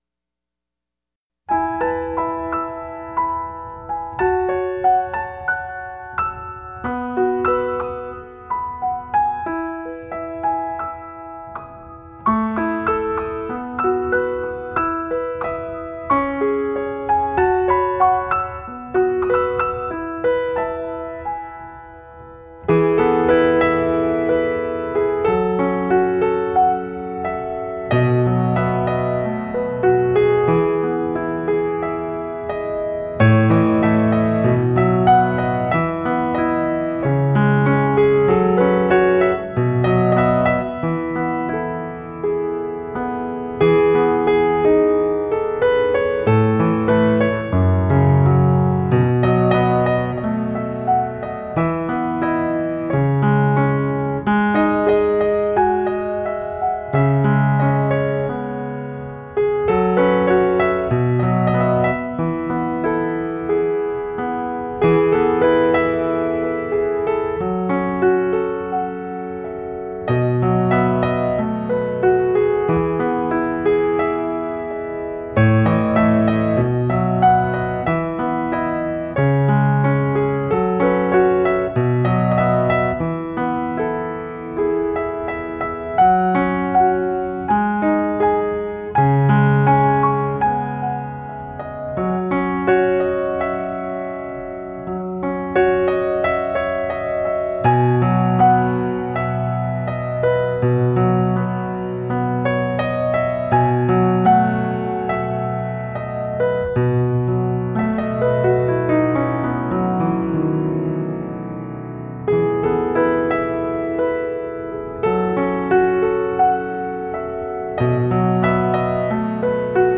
洗尽铅华后的素净琴声